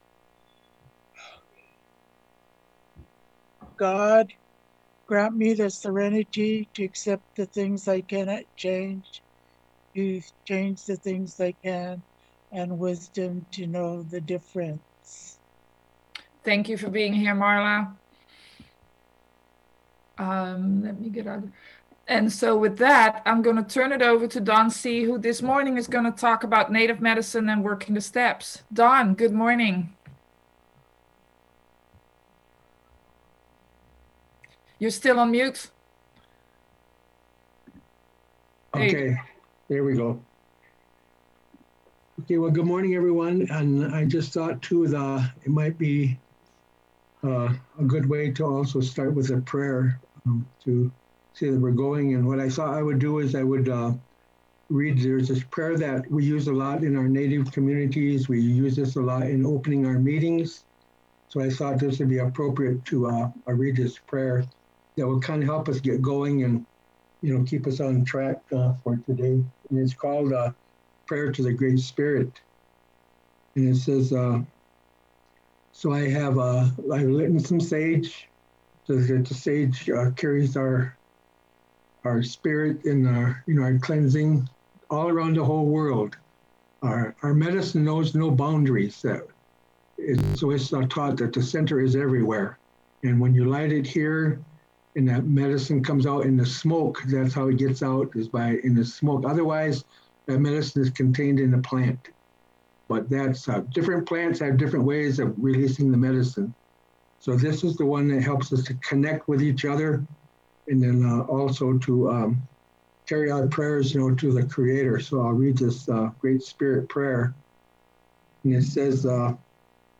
American Indian Conference - AWB Roundup Oct 17-18